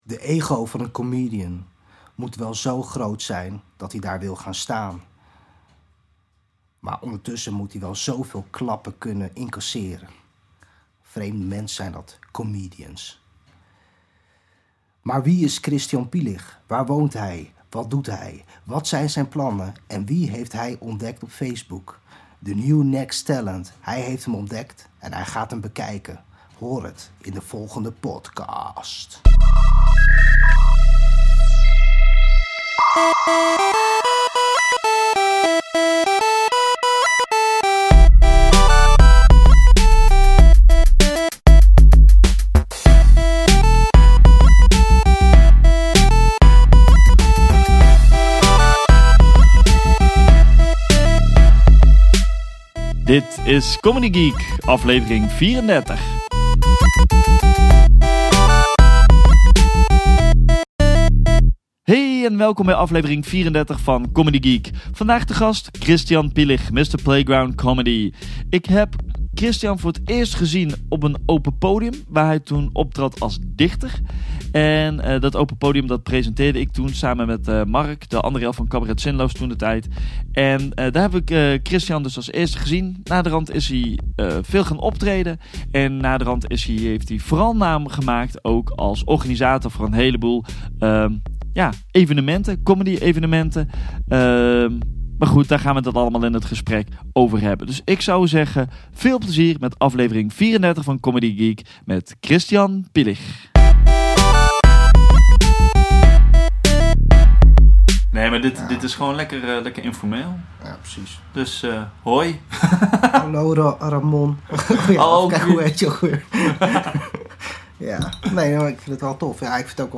In deze podcast geen interviews, maar openhartige en eerlijke gesprekken aan de keukentafel bij de artiest thuis.